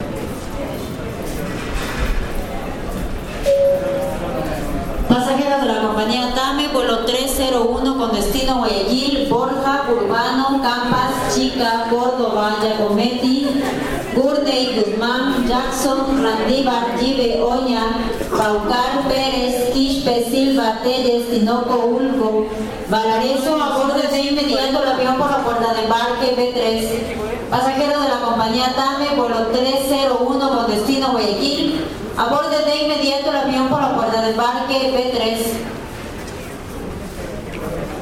spanish airport anouncement
airport anouncement chimes ding ecuador female field-recording new-quito-airport sound effect free sound royalty free Sound Effects